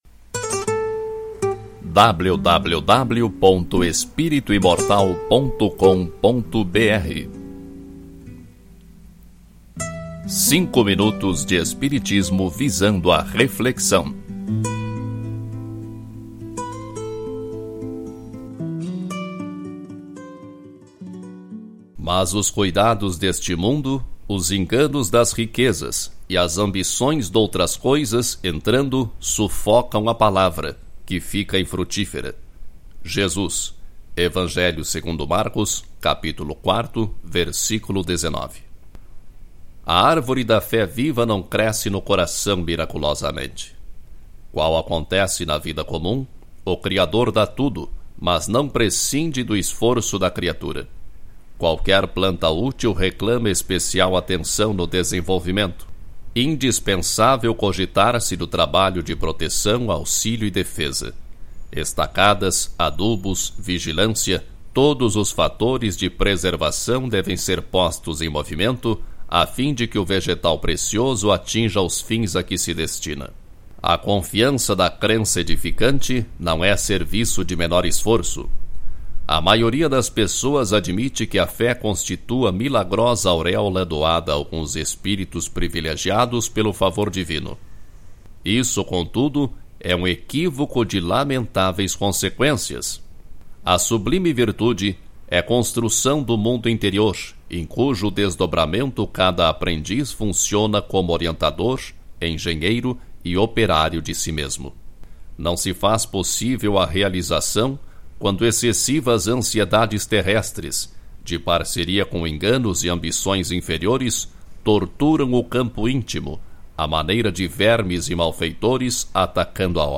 Mensagem em áudio